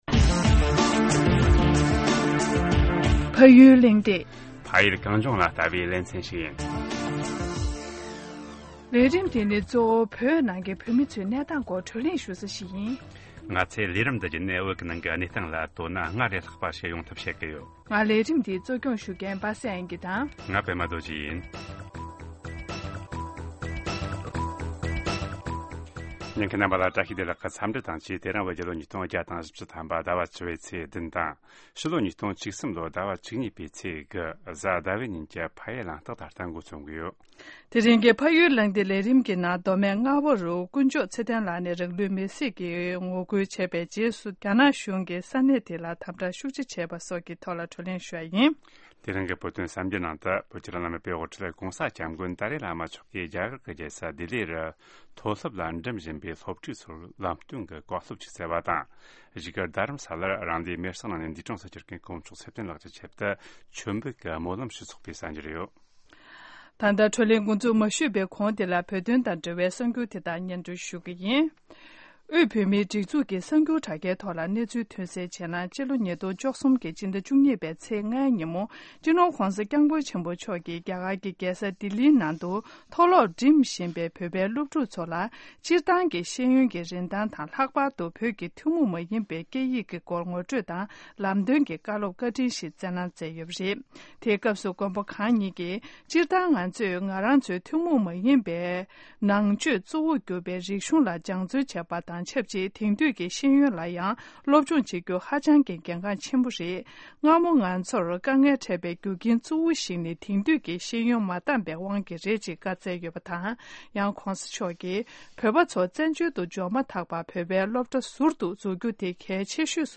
བགྲོ་གླེང་ཞུས་པ་ཞིག་གསན་རོགས་གནང་།